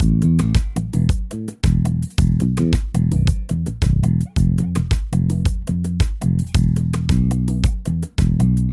لوپ 6/8 گیتار بیس 1 دانلود سمپل گیتار بیس بستکی
لوپ 6/8 گیتار بیس 1 کامل ترین سمپل پک گیتار بیس با کیفیت فوق العاده بالا مخصوص آهنگ های مارکتی و بستکی 6/8 در پترن ها و فیگور های ریتمی جذاب
demo-bass-guitar-vol1.mp3